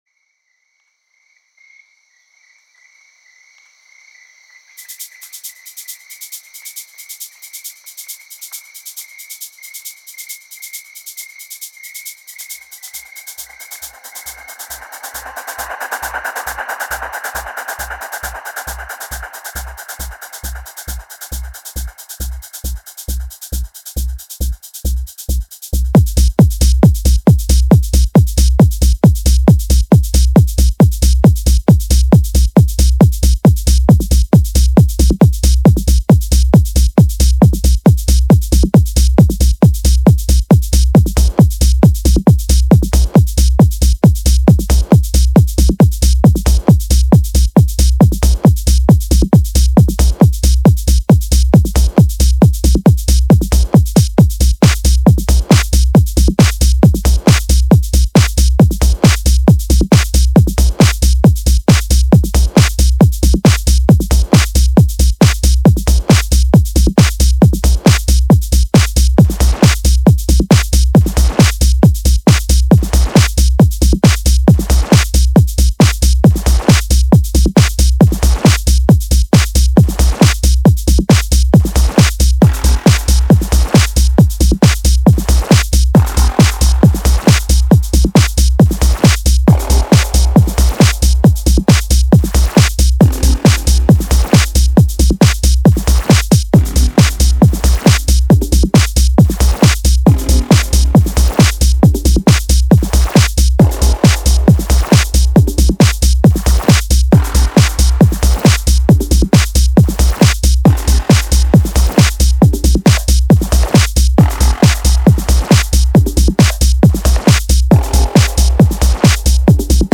Techhouse 2 Techno ist der Flow